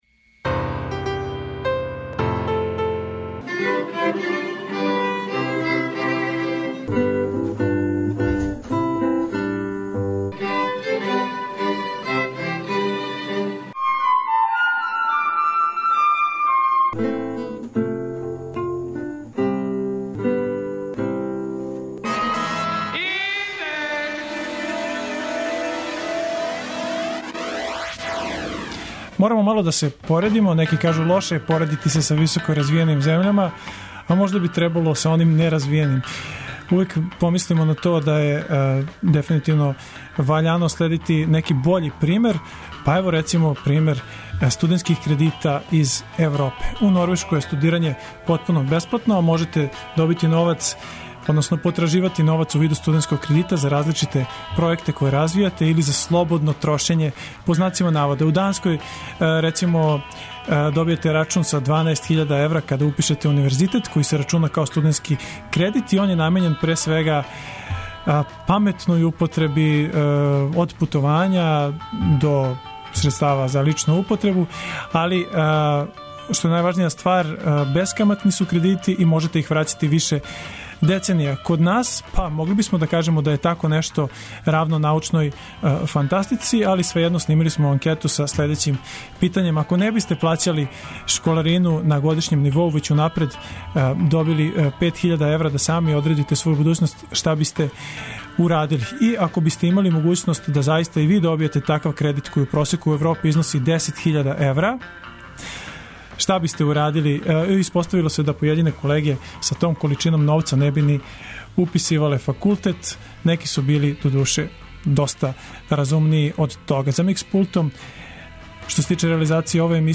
За нас је тако нешто још увек научна фантастика, али смо свеједно снимили анкету са следећим питањем: Aко не бисте плаћали школарину на годишњем нивоу већ унапред добили пет хиљада евра (просек укупне српске школарине) да сами одредите своју будућност, шта бисте урадили?